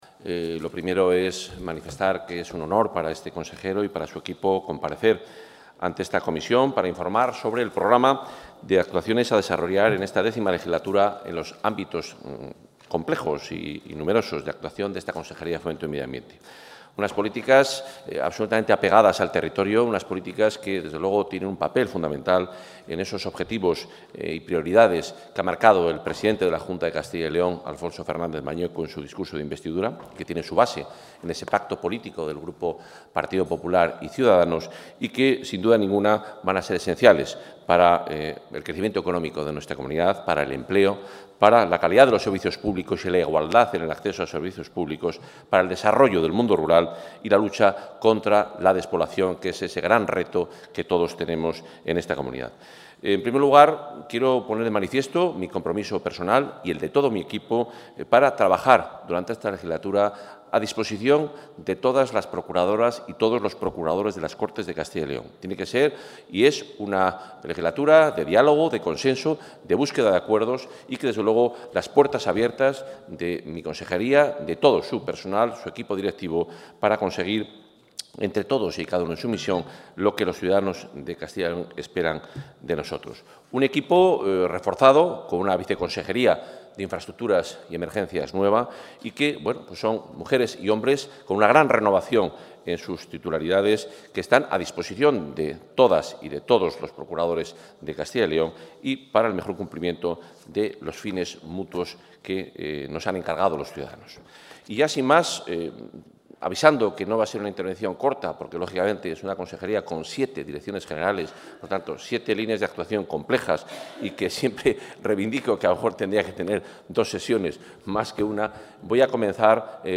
Audio comparecencia.
El consejero de Fomento y Medio Ambiente, Juan Carlos Suárez-Quiñones, ha expuesto hoy en las Cortes de Castilla y León los principales ejes de actuación de su departamento para estos cuatro años. La lucha contra la despoblación marcará las políticas en la Consejería de Fomento y Medio Ambiente.